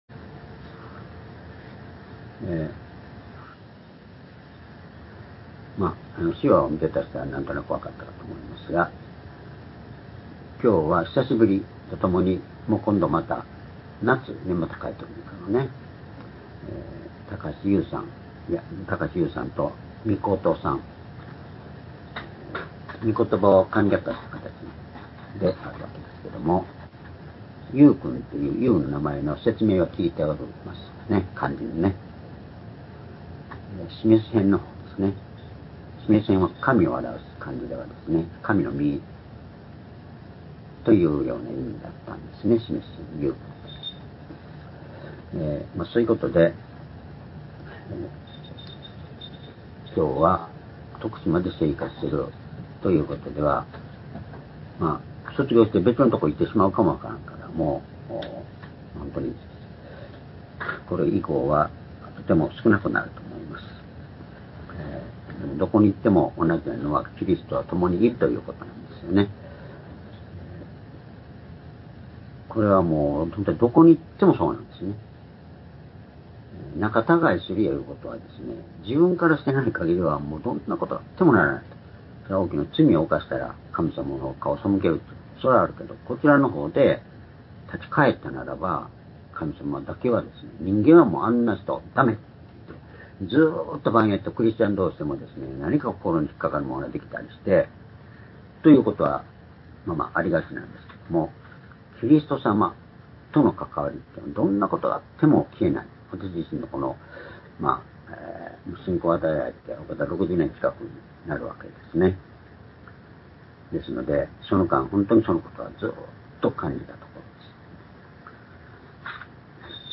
主日礼拝日時 2025年3月２３日(主日礼拝) 聖書講話箇所 「主からの呼びかけ」 ヨハネ20章11～16節 ※視聴できない場合は をクリックしてください。